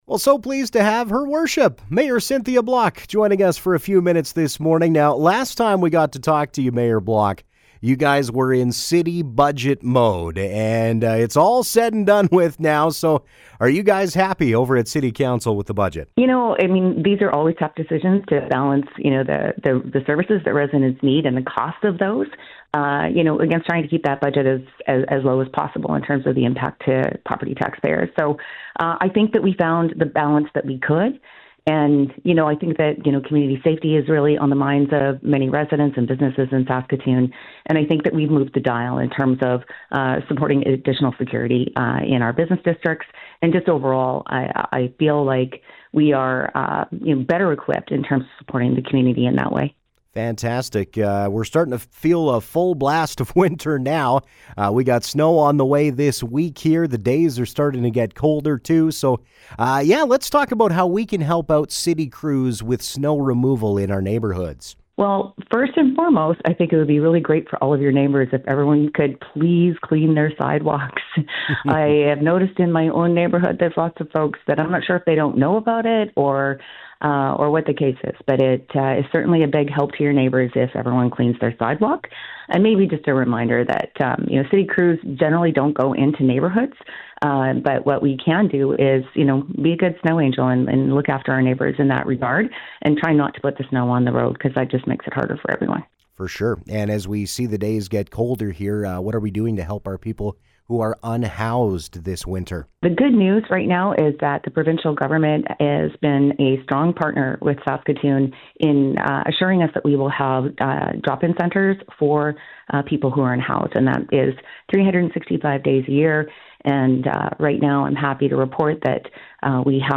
Her Worship Mayor Cynthia Block joined us to talk about the city budget, looking after our homeless people during the cold winter months, entertainment in Saskatoon in 2026 and some Christmas traditions in her household.
mayor-cynthia-block-december-9.mp3